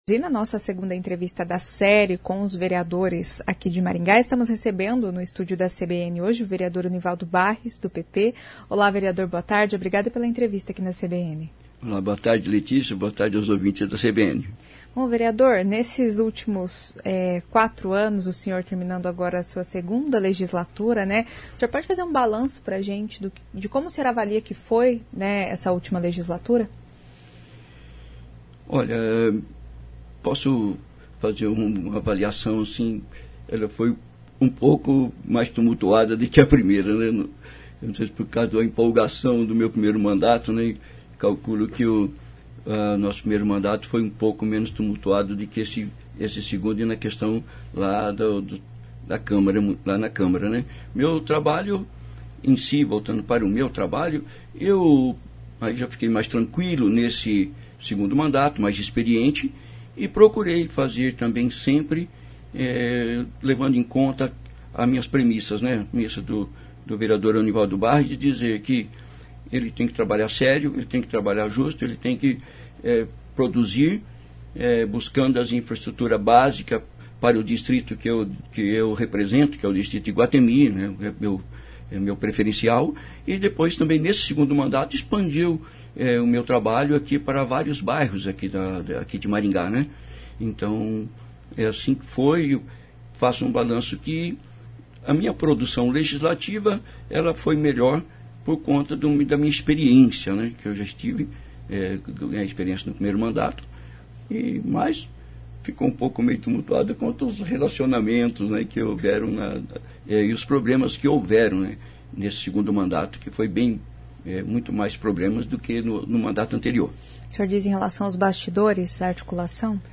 Nesta Conversa o vereador Onivaldo Barris (PP) fez um balanço das duas legislaturas que cumpre como vereador.